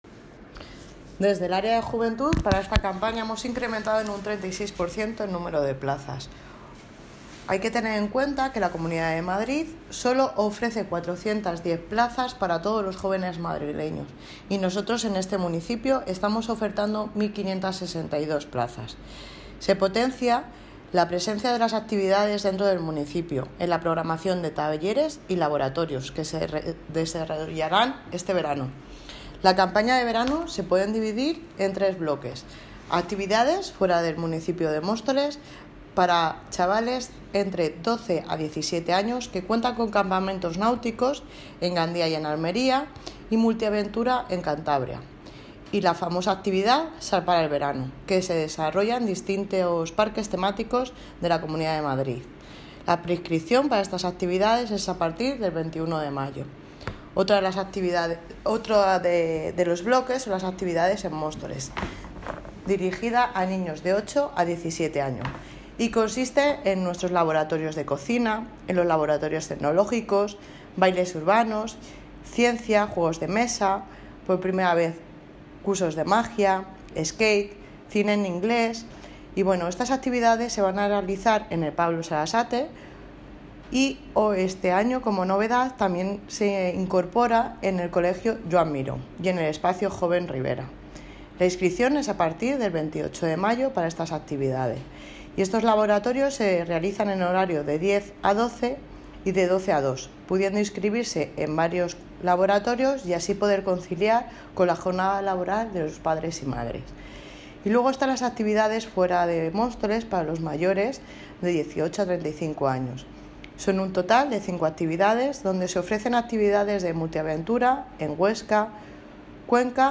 Audio - Aránzazu Fernández (Concejala de Juventud y Participación Ciudadana) Sobre Campaña De Verano